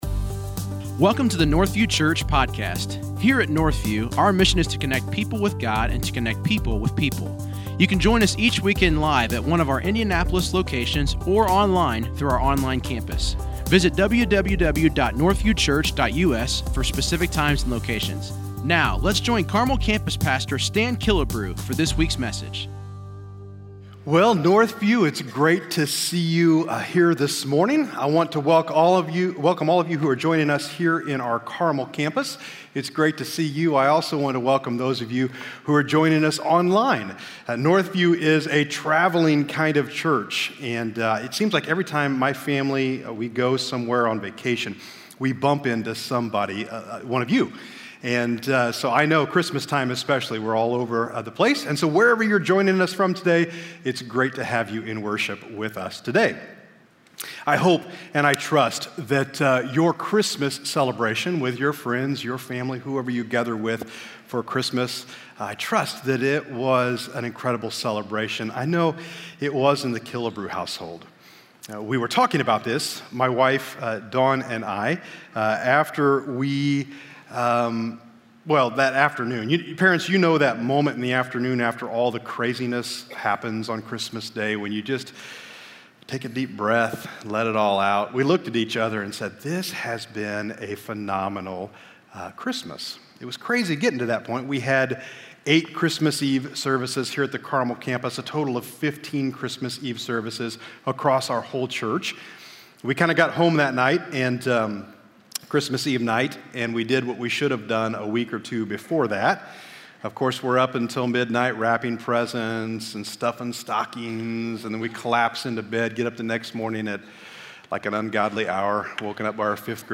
If you’ve ever wondered what the Bible has to say about happiness, join us this weekend as the campus pastors talk about how to live a happy life.